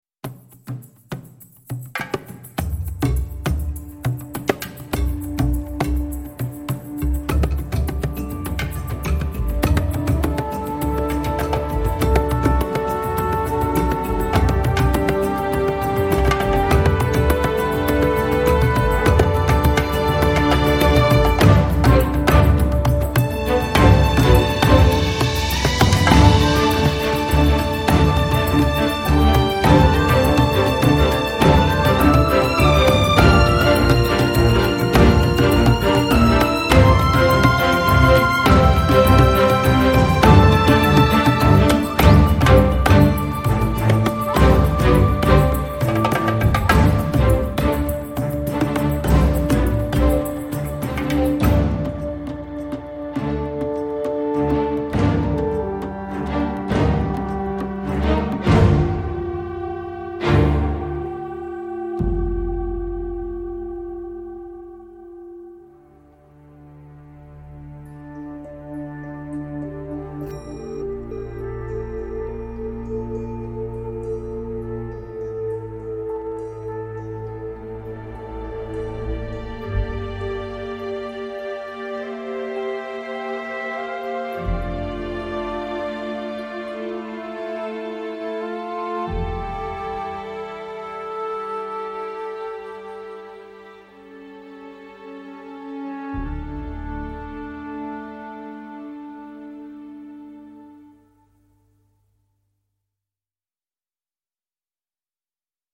Ailleurs encore, des moments d’émotion délicate et sincère.